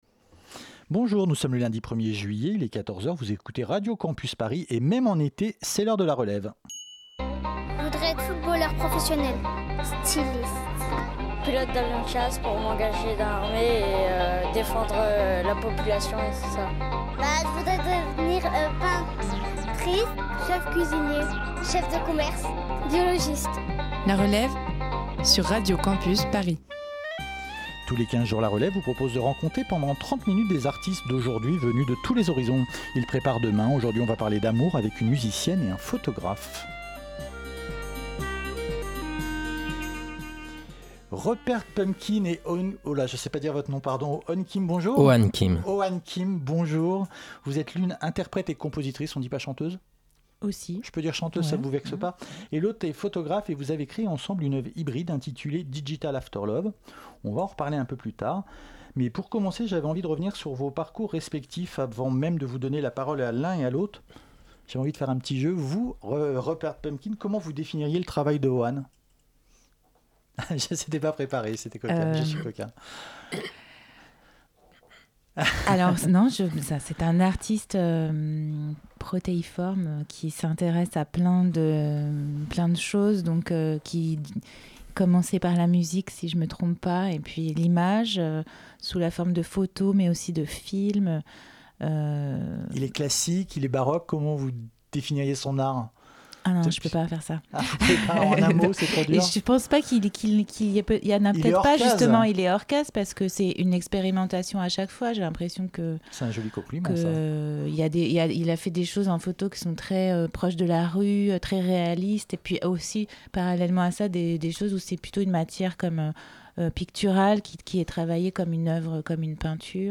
01 07 19 Partager Type Entretien Culture samedi 27 juillet 2019 Lire Pause Télécharger Crédit photo